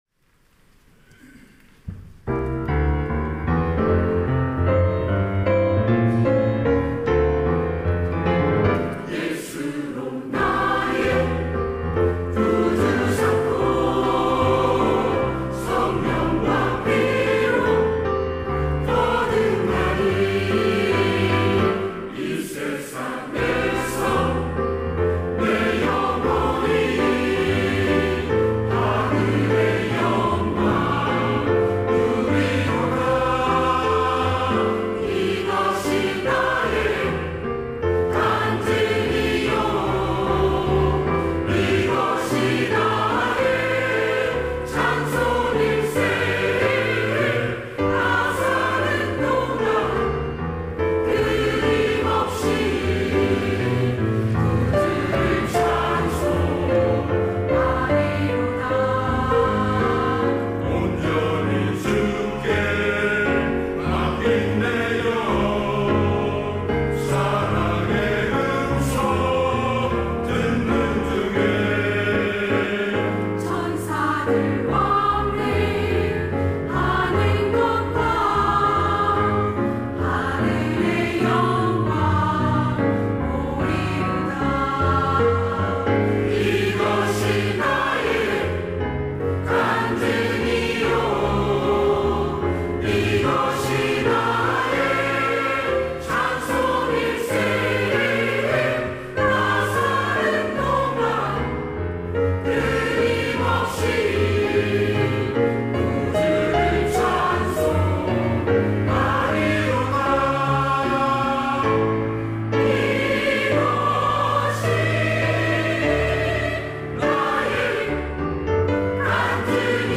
시온(주일1부) - 예수로 나의 구주 삼고
찬양대